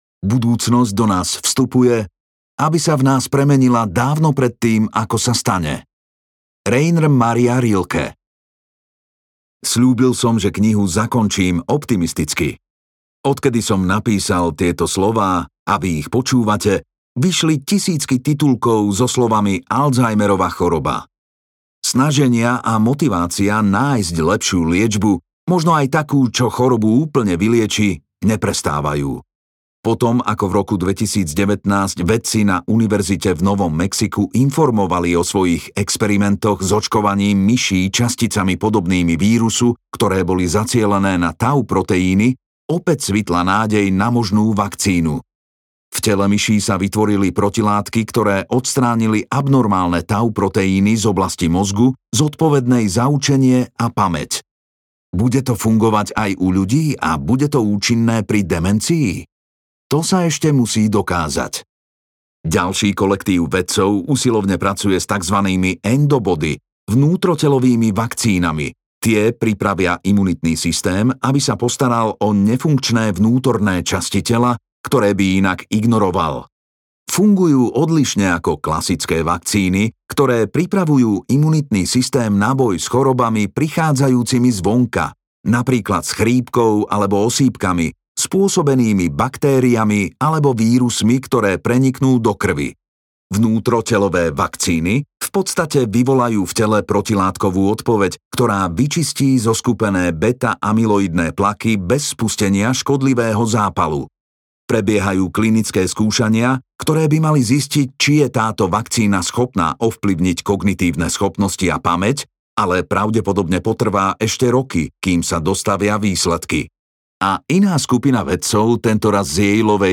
Zachovajte si bystrosť audiokniha
Ukázka z knihy